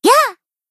BA_V_Hoshino_Swimsuit_Battle_Shout_3.ogg